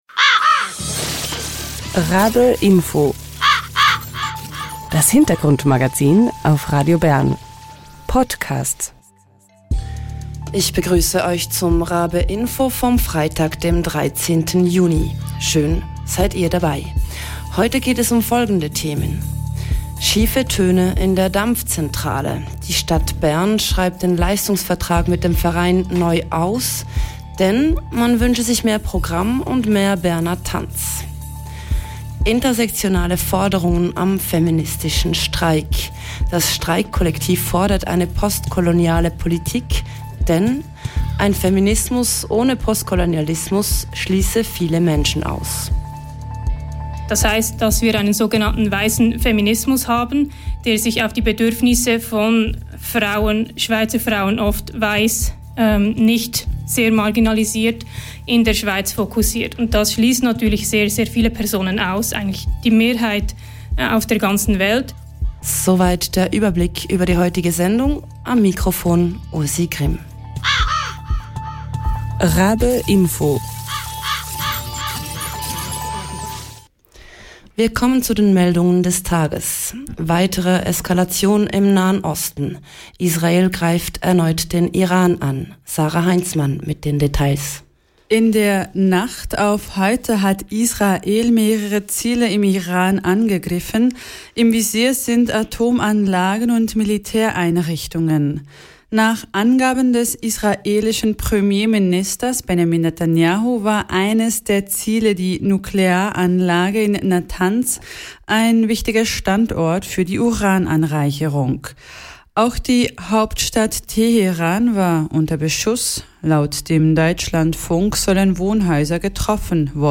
Im Interview reden zwei Mitglieder des Berner Streikkollektivs über ihre Forderungen, die mehr als nur die Anliegen von Schweizer Frauen umfassen.